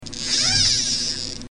Fishing reel 3